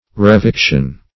Search Result for " reviction" : The Collaborative International Dictionary of English v.0.48: Reviction \Re*vic"tion\, n. [From L. revivere, revictum, to live again; pref. re- re- + vivere to live.] Return to life.